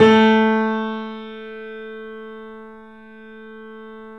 Index of /90_sSampleCDs/E-MU Producer Series Vol. 5 – 3-D Audio Collection/3D Pianos/YamaMediumVF04